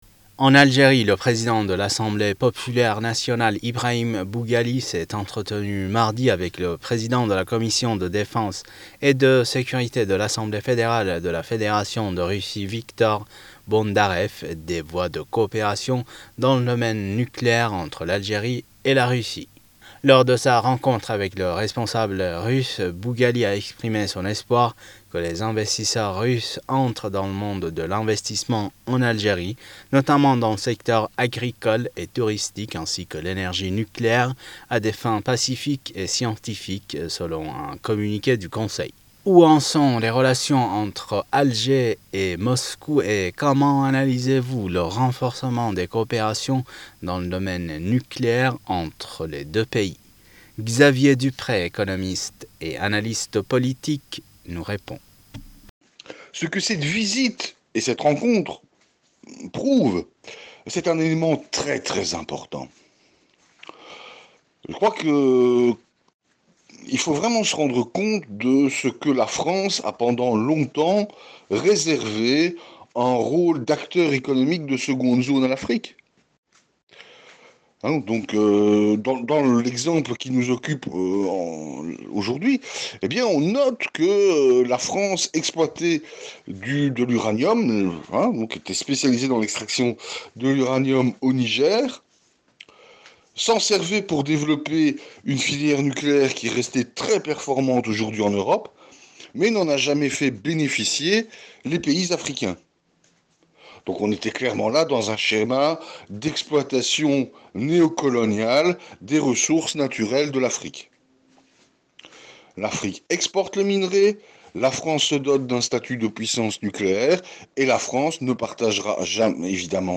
économiste et analyste politique, s'exprime sur le sujet.